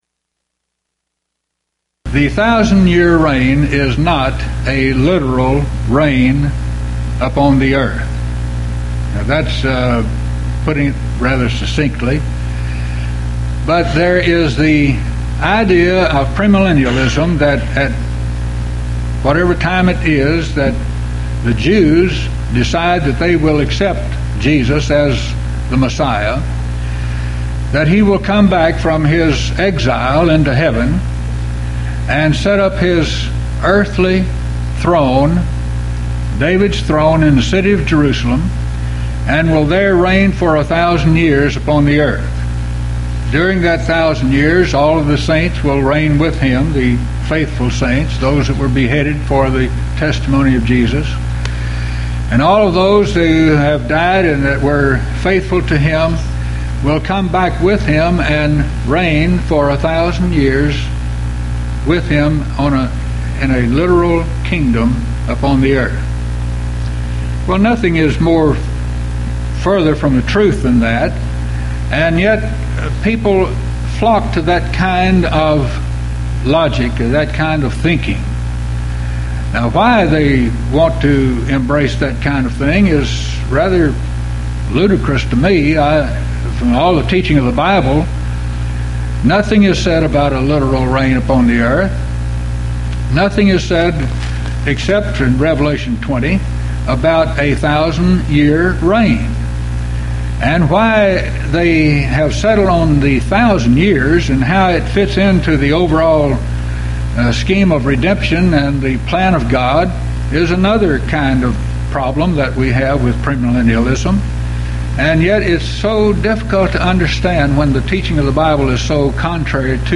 Event: 1997 HCB Lectures Theme/Title: Premillennialism
lecture